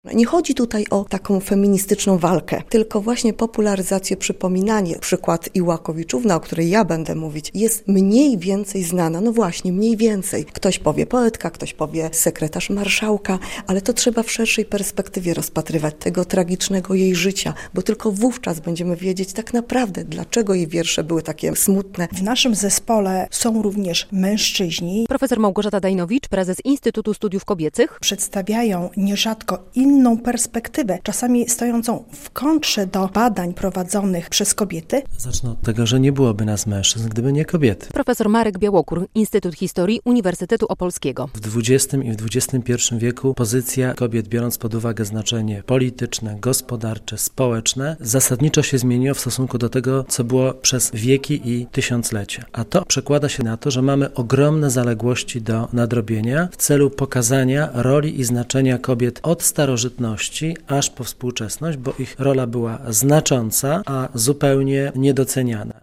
Rola kobiet w życiu publicznym - relacja